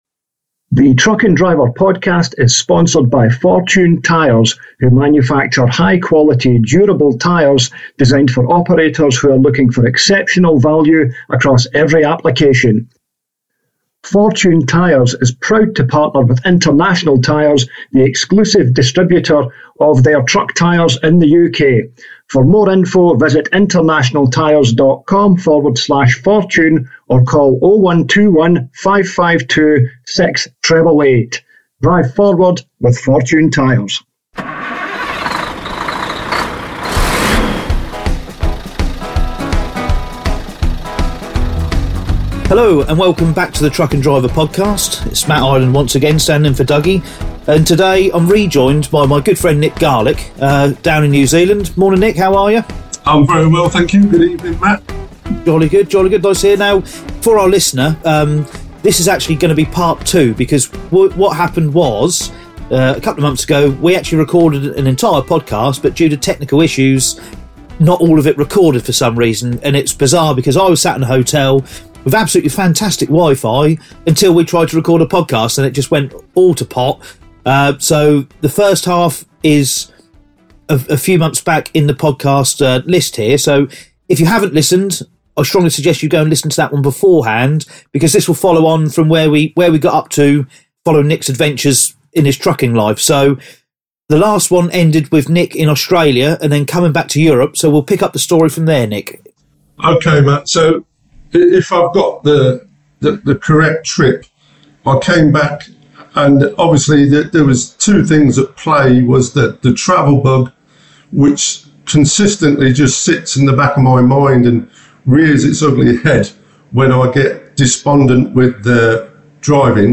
Truck & Driver interview